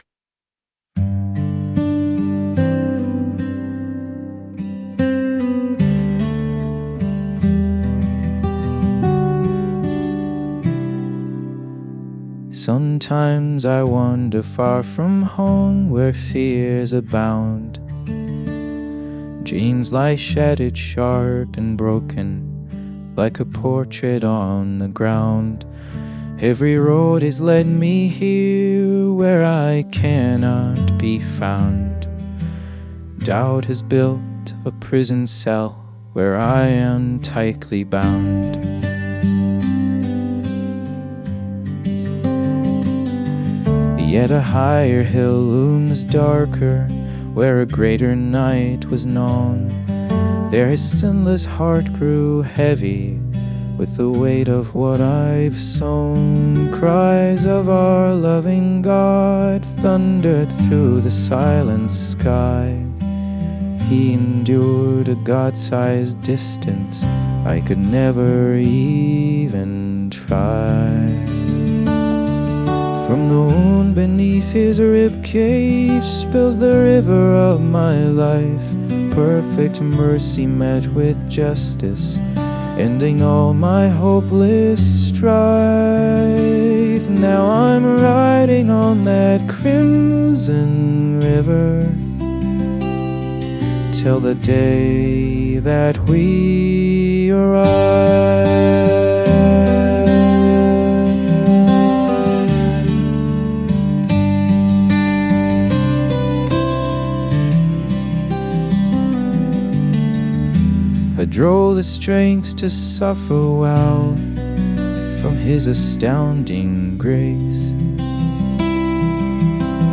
Music synthesized as AI accompaniment/autotune.
An original hymn